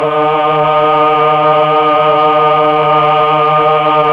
Index of /90_sSampleCDs/Roland LCDP09 Keys of the 60s and 70s 1/VOX_Melotron Vox/VOX_Tron Choir